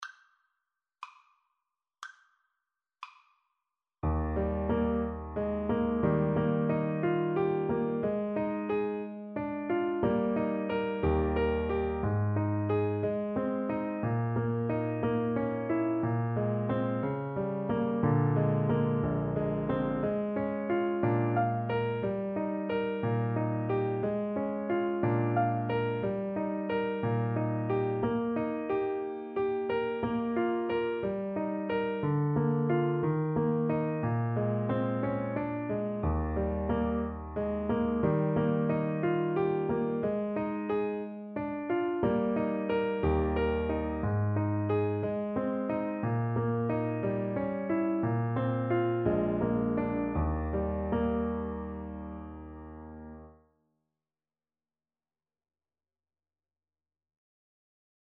Play (or use space bar on your keyboard) Pause Music Playalong - Piano Accompaniment Playalong Band Accompaniment not yet available transpose reset tempo print settings full screen
~ = 60 Andantino (View more music marked Andantino)
Eb major (Sounding Pitch) F major (Clarinet in Bb) (View more Eb major Music for Clarinet )
2/4 (View more 2/4 Music)
Classical (View more Classical Clarinet Music)